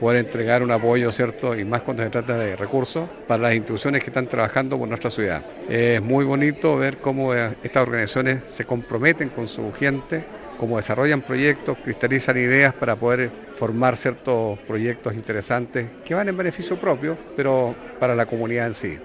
El alcalde de Osorno, Jaime Bertin, destacó que estos fondos permitirán a las agrupaciones continuar con sus actividades sociales.